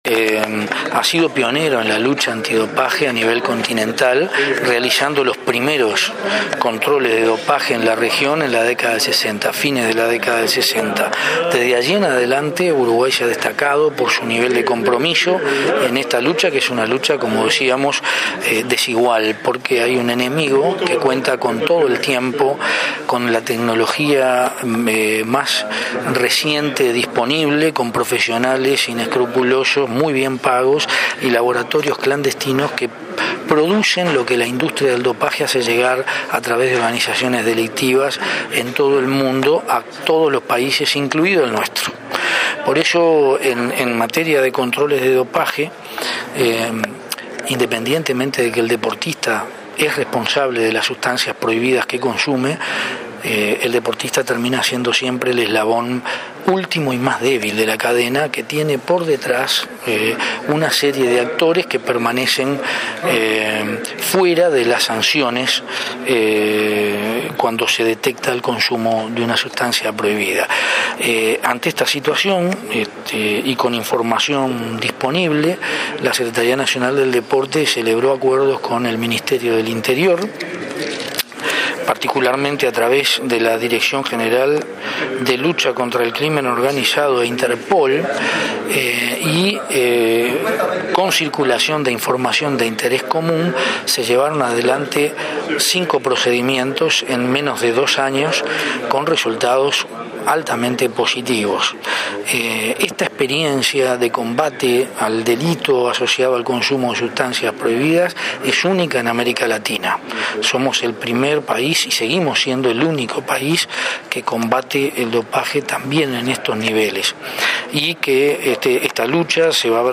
Uruguay es pionero en la lucha antidopaje en el continente. Así lo subrayó el secretario del Deporte, Fernando Cáceres, durante la presentación del carné de salud digital para el deportista. Mediante acuerdo con el Ministerio del Interior, se realizaron cinco procedimientos en menos de dos años con más de 20 procesados y se incautaron más de 100.000 dólares en sustancias prohibidas para el uso en el deporte.